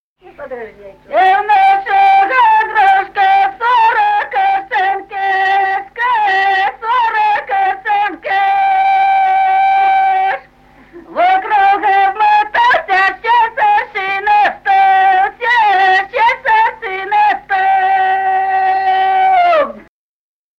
Песни села Остроглядово. И в нашего дружка.